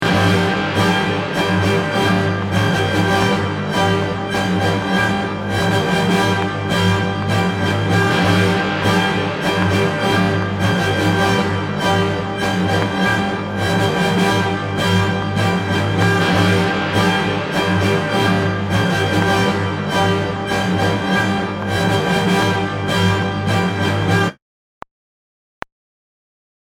It is very difficult because the drums are not playing to a steady time.
The rhythm is difficult to count, it might be easier to just listen and follow the music!
MARS - Lesson 3 - PIECE 1 - slower version.mp3